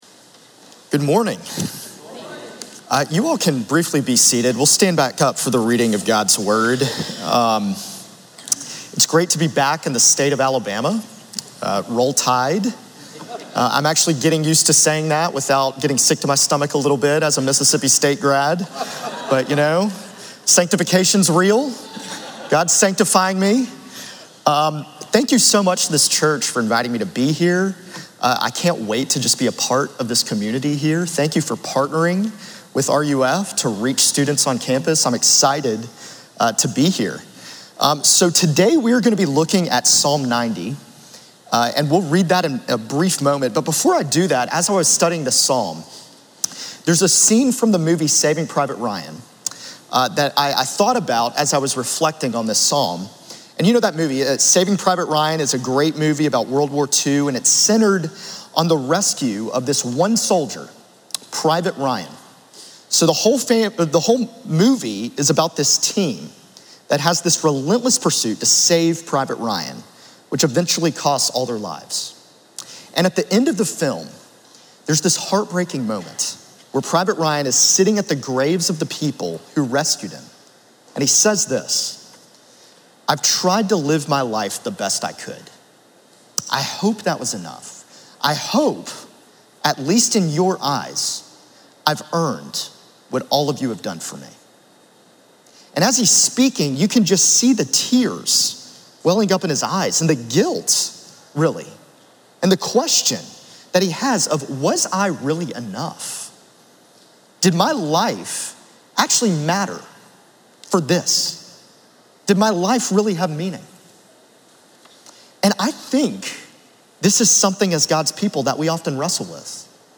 « Back to sermons page Timeless Meaning for a Transient Life Sermon from July 27